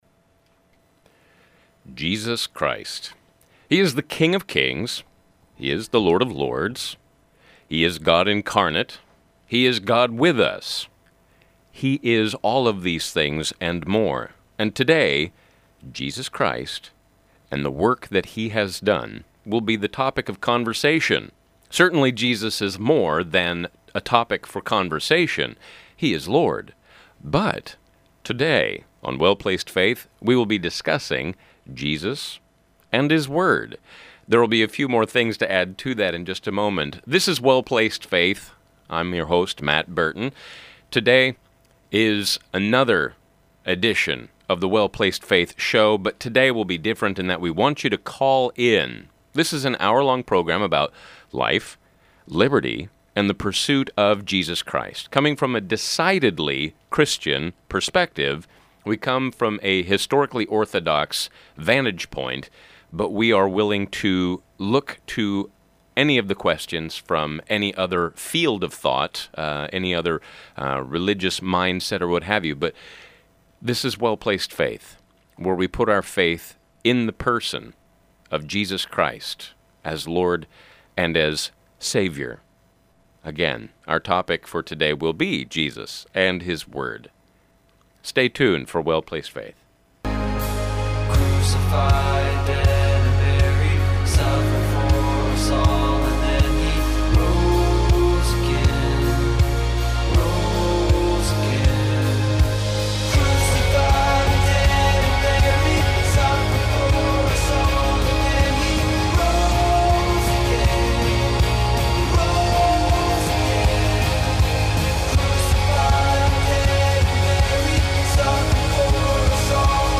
Dec 30, 2014 – Call-In Program!